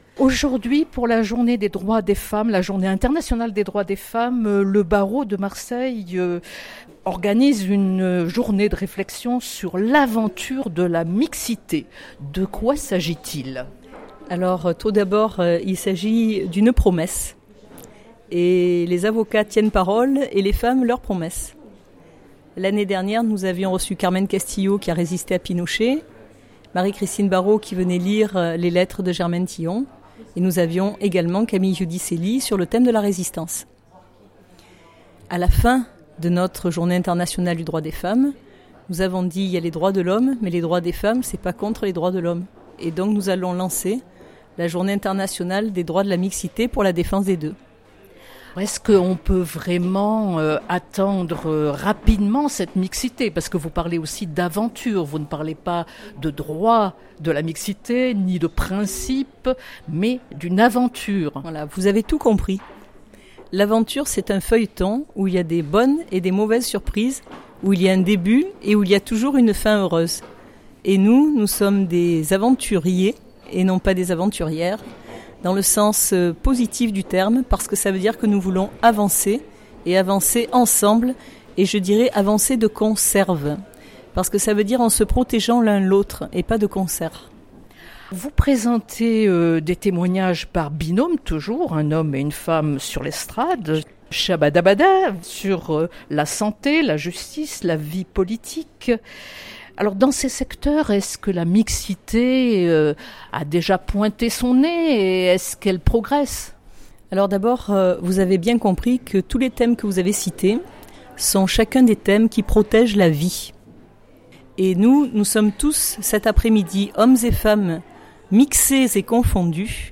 Entretiens